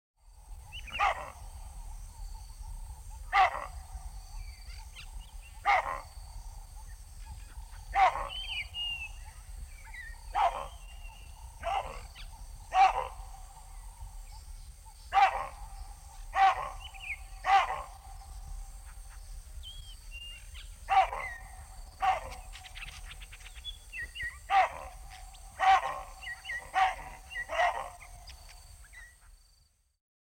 На этой странице собраны разнообразные звуки бабуина — от громких криков до ворчания и общения в стае.
Звук желтого павиана в дикой природе